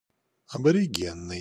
Ääntäminen
UK : IPA : [ˌæb.ə.ˈɹɪdʒ.ə.nəl]